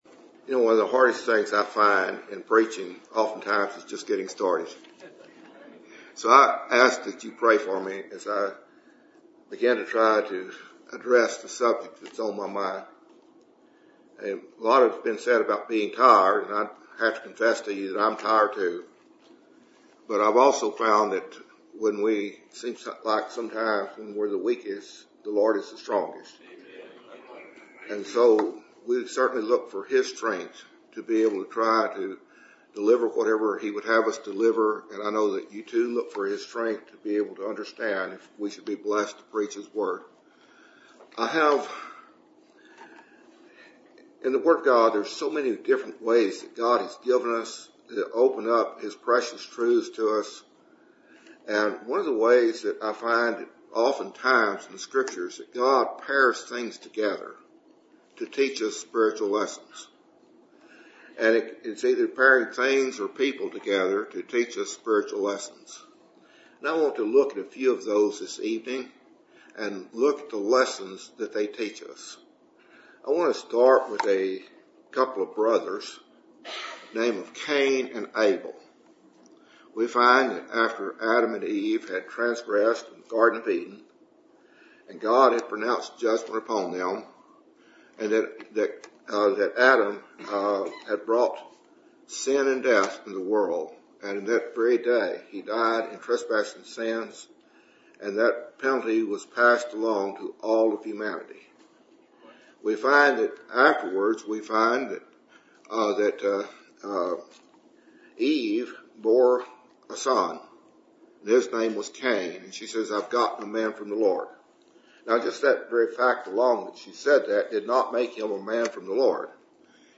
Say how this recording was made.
Service Type: Ebenezer Fellowship Meeting